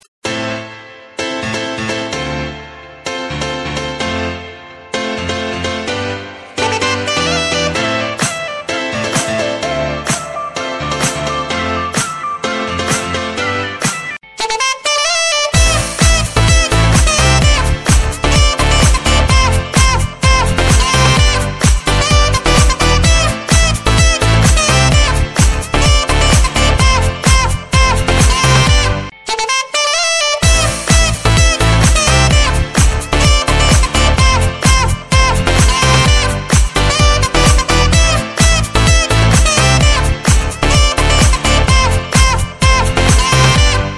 • Качество: 128, Stereo
позитивная